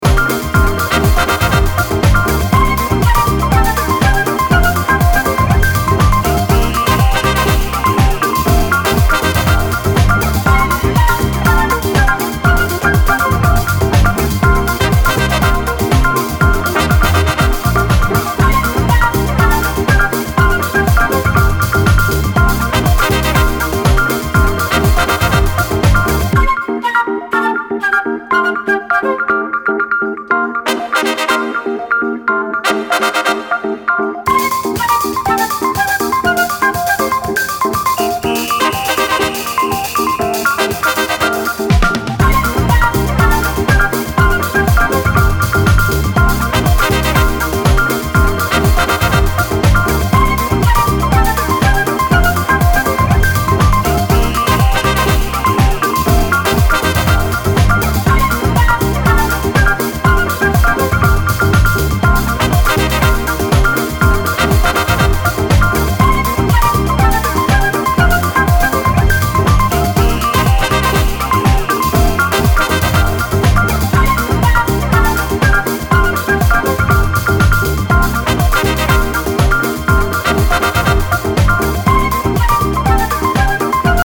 full on remix produced directly from the actual multitracks